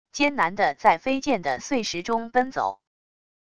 艰难的在飞溅的碎石中奔走wav音频